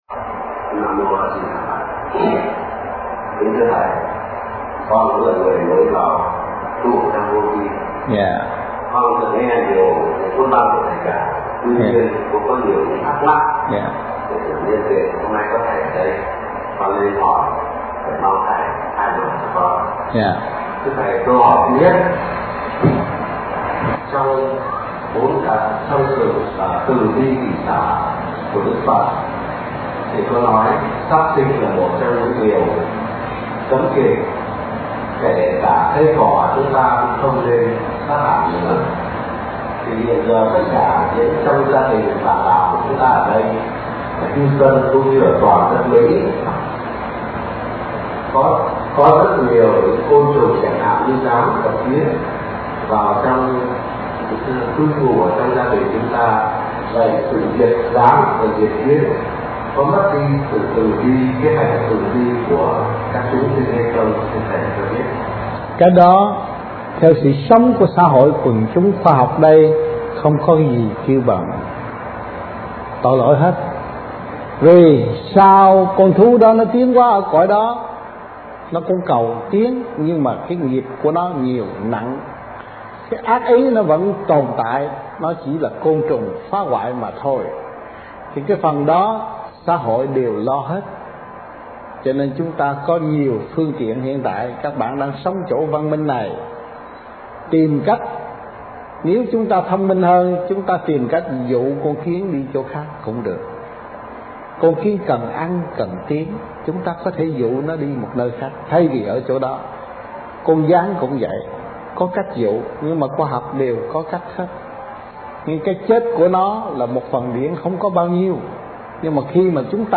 Questions and Answers